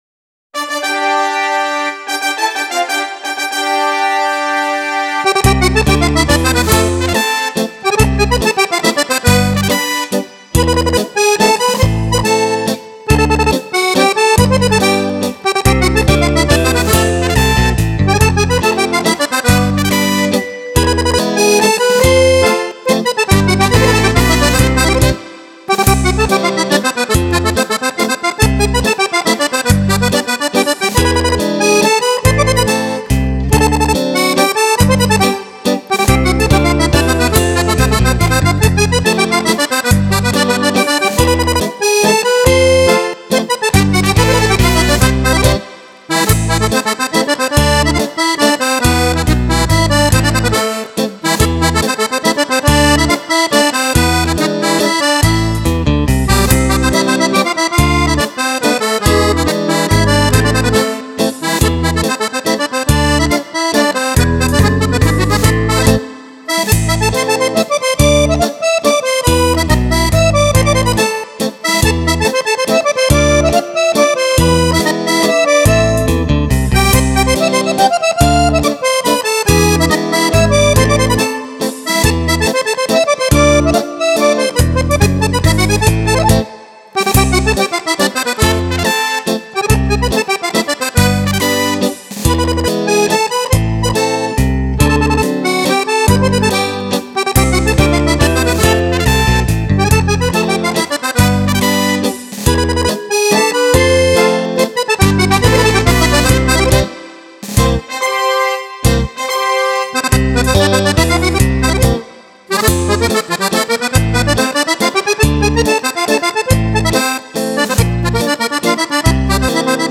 Mazurka
10 ballabili per Fisarmonica
Fisarmonica
Chitarre